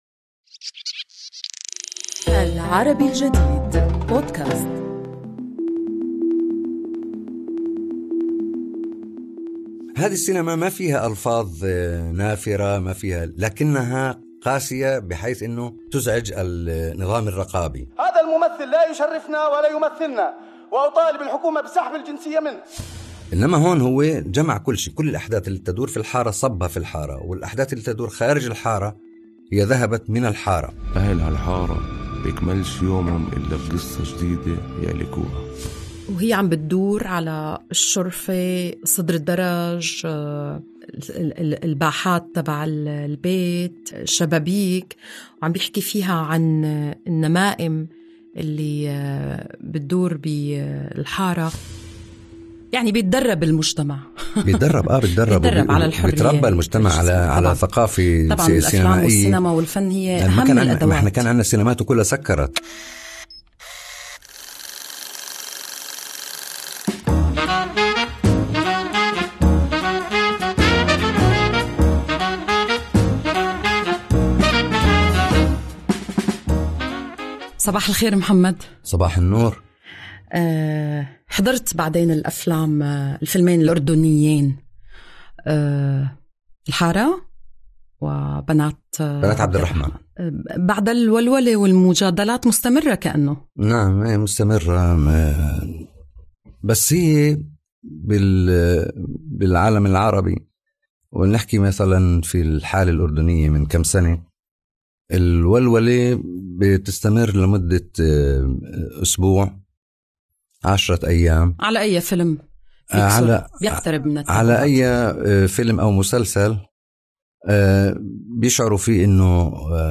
في هذا البودكاست نقاش حول الفيلمين والجدل المستمر عليهما